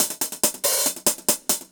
Index of /musicradar/ultimate-hihat-samples/140bpm
UHH_AcoustiHatB_140-05.wav